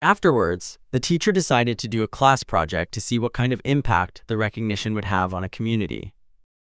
Text-to-Speech
Synthetic